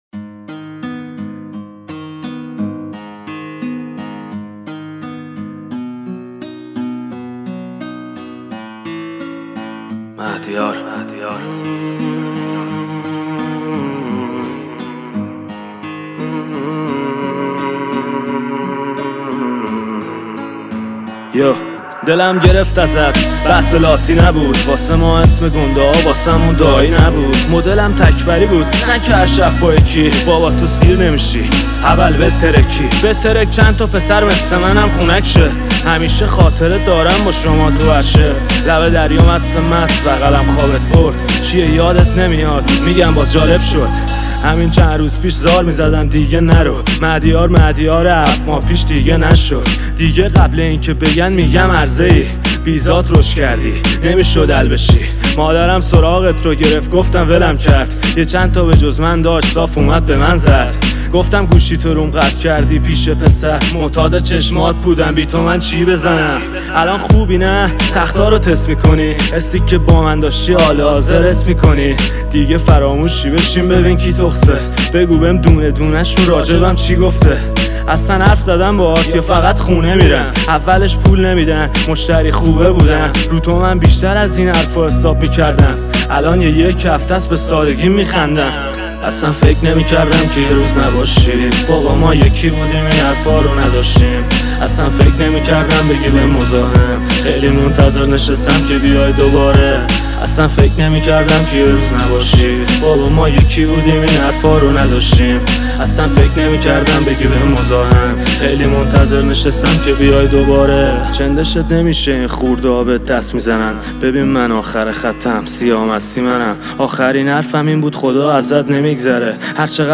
موزیک لاتی